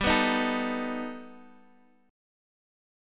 G+add9 chord {x 10 9 8 10 x} chord